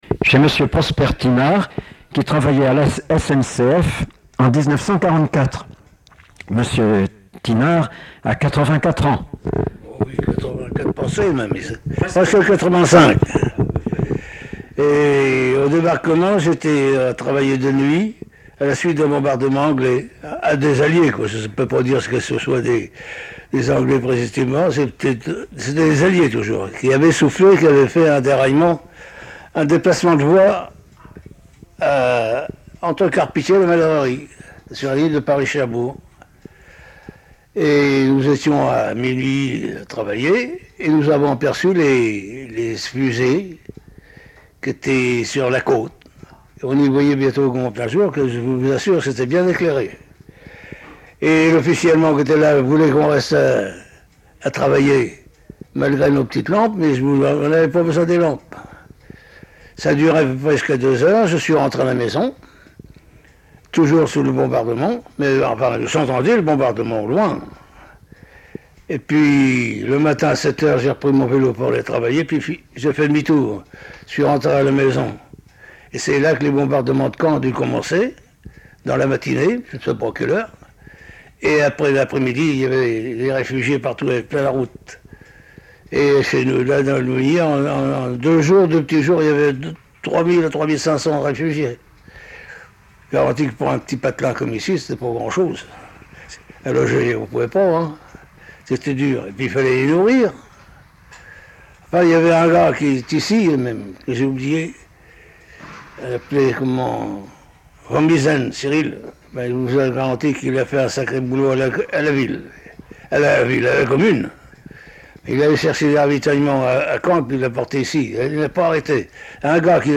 Cette interview est issue du fonds d'enregistrements de témoignages oraux relatifs à la Seconde Guerre mondiale conservés et archivés au Mémorial de Caen, que le musée a gracieusement mis à la disposition de la Maison de la Recherche en Sciences Humaines dans le cadre du projet de recherche Mémoires de Guerre.